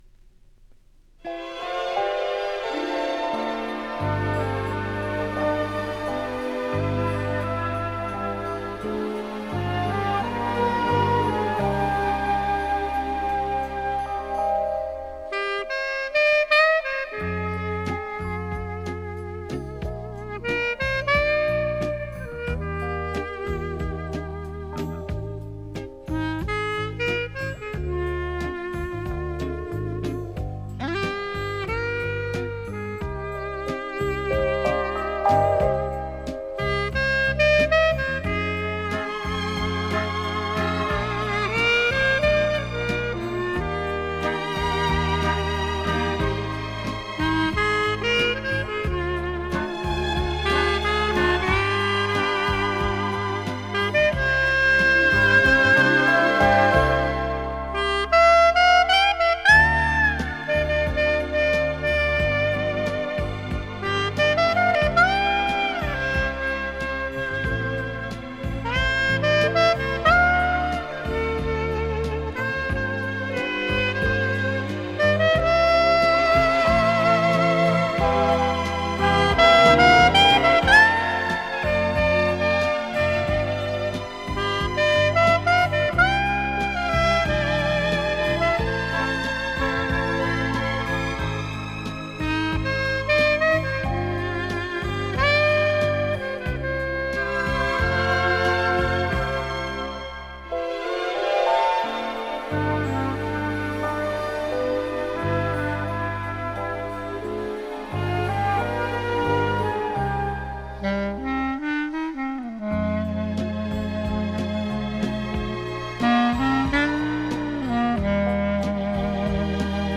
Вот ещё осенняя душевная мелодия